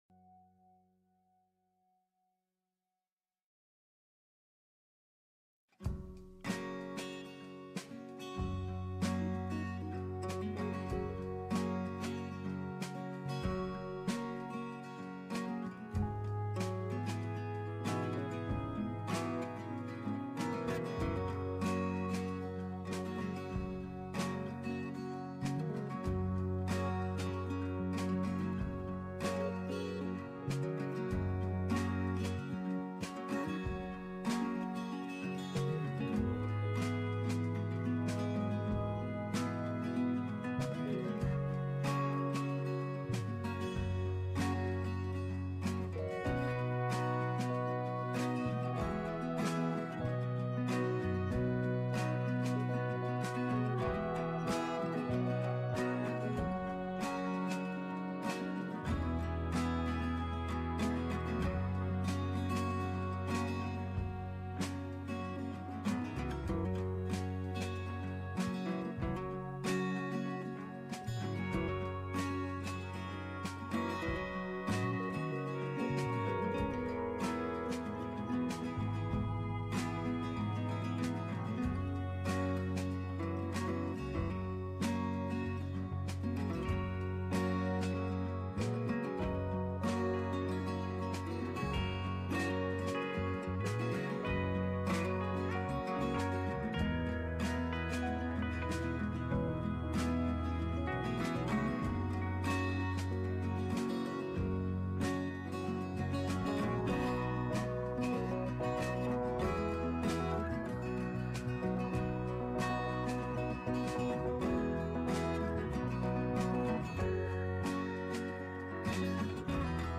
The-Backstory-on-Longmont-Public-Media-LIVE.mp3